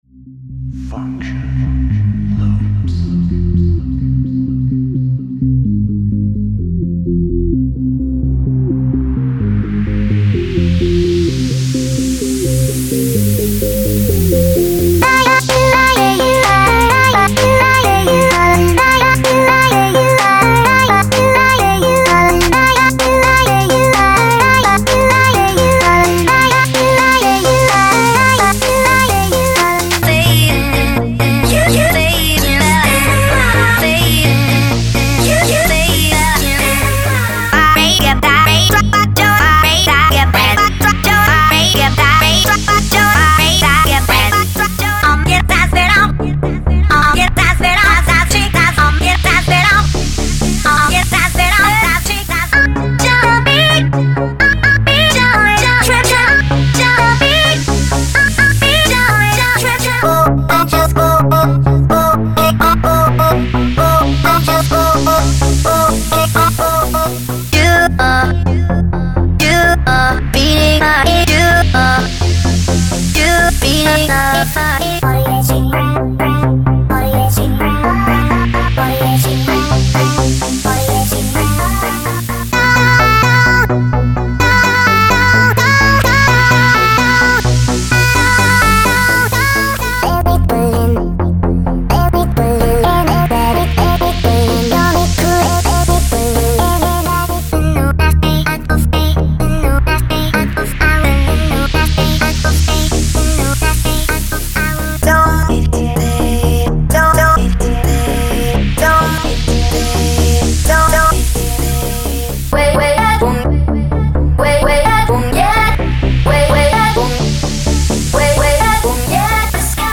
这些声音剪辑非常适合Future and Progressive House，EDM，Trance和其他应用。
•49个干人声循环
•49个湿人声循环